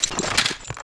crossbow_rdy.wav